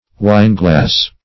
Wineglass \Wine"glass`\, n.